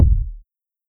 Kick Groovin 1.wav